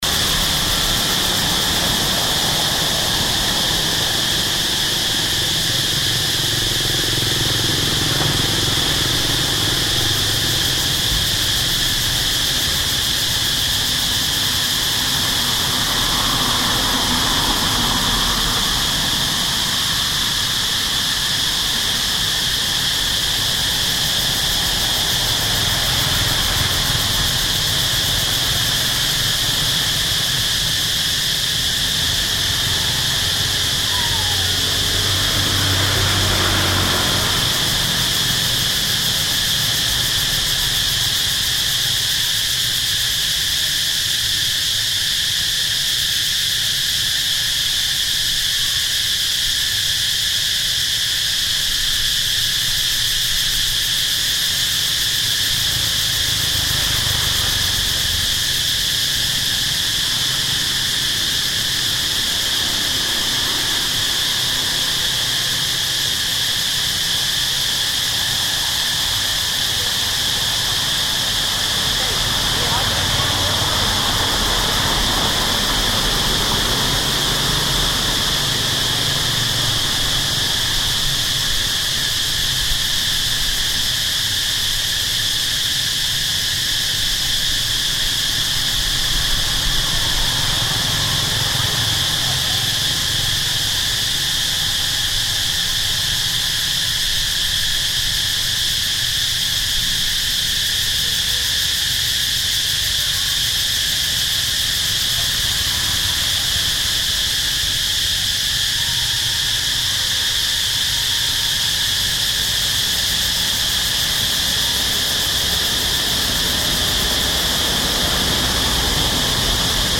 Night sound with locusts